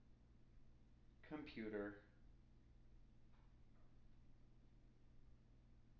wake-word
tng-computer-32.wav